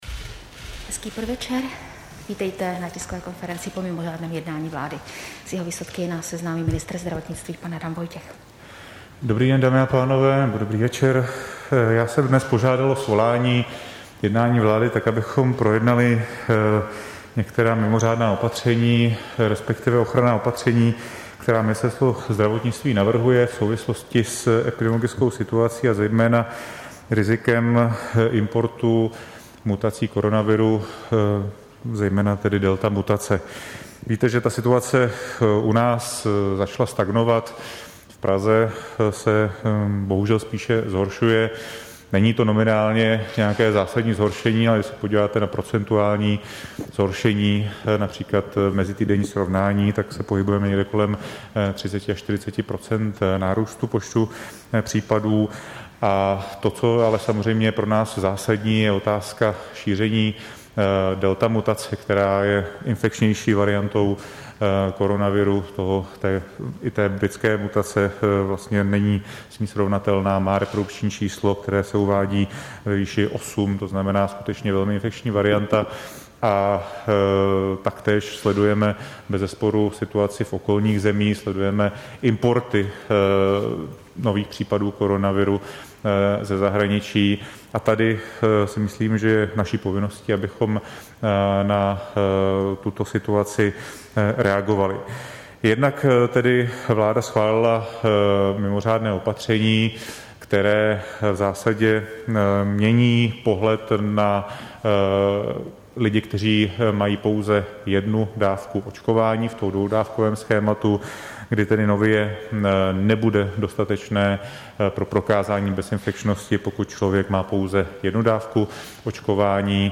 Tisková konference po mimořádném jednání vlády, 1. července 2021